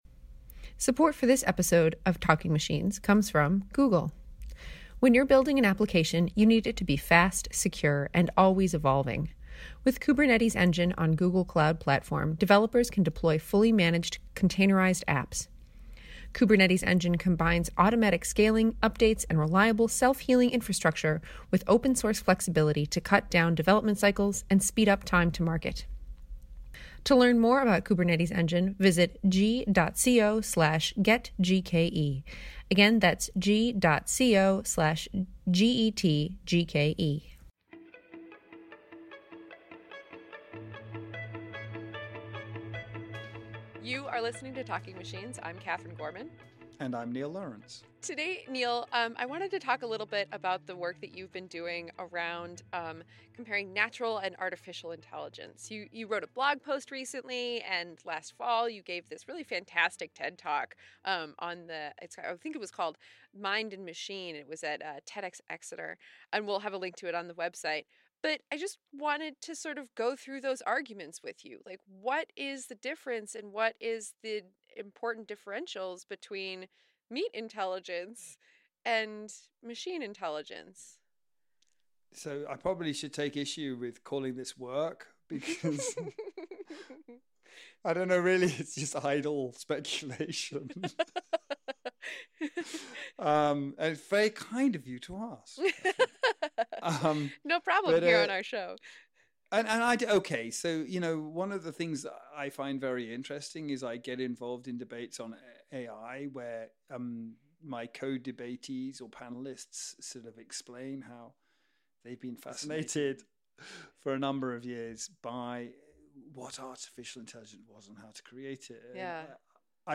We got a chance to speak to both of them about their work and the event.